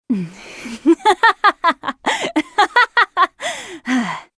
Risada Riven (LoL)
Risada da lutadora Riven, a Exilada, de League Of Legends (LoL).
risada-riven-lol.mp3